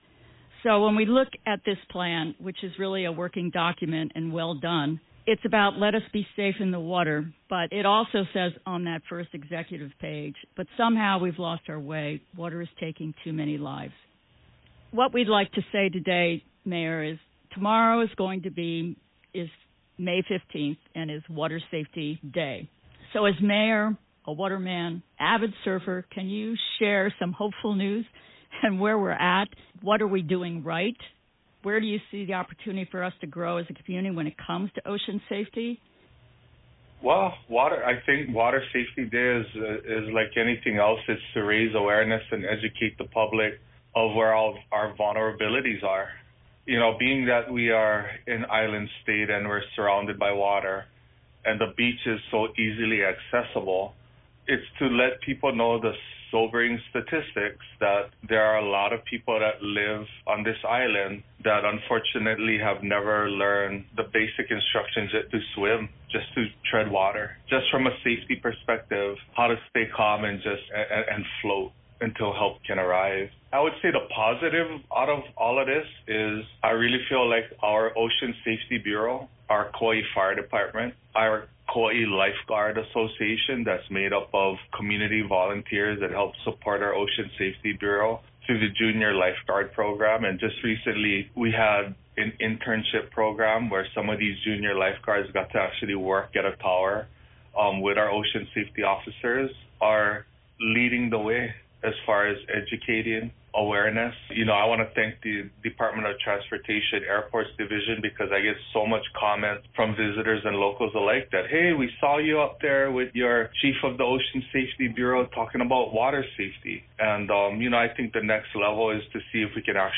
Take a moment to listen to voices from across the state, the nation, and the world, as they share their stories about water safety:
Deep-Dive-Highlight-Reel-Final-1.mp3